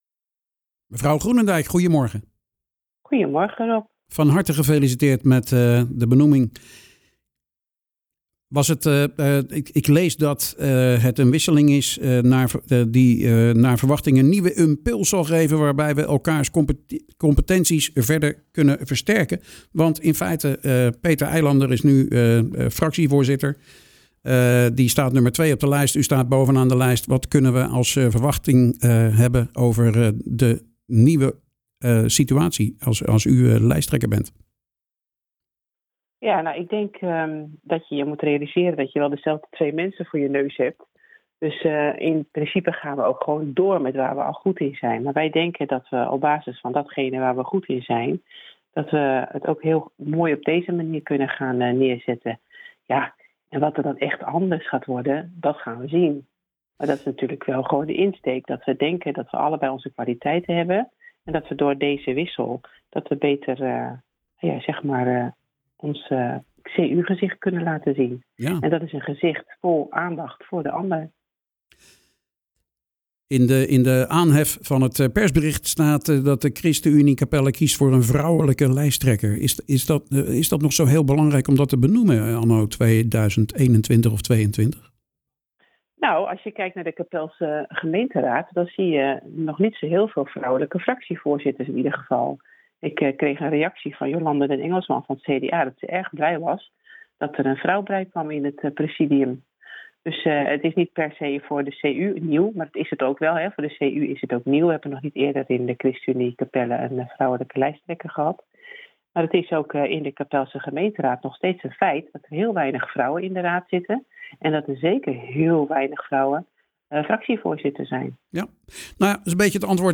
praat met de lijsttrekker over het bijzondere van een vrouwelijke lijsttrekker, Angela Merkel als voorbeeld en over 'ruimte om in te stralen'.